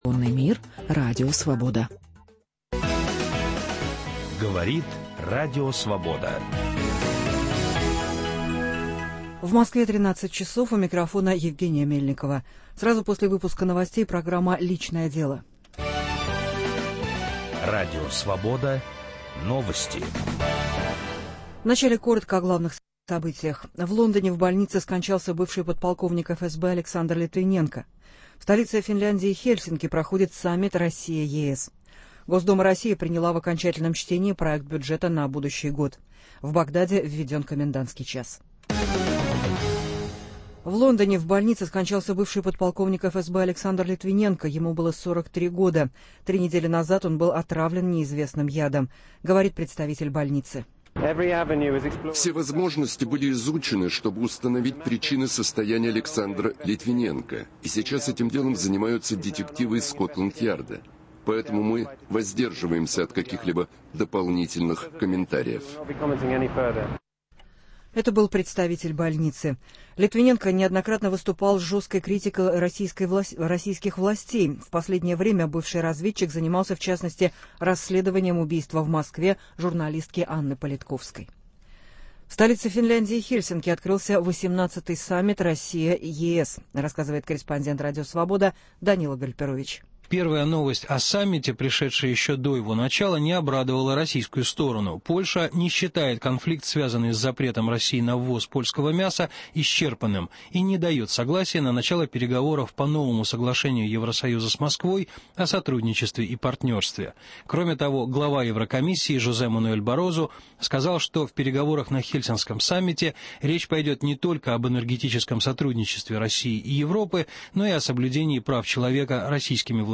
Об этом в прямом эфире мы будем говорить с писателем, психологом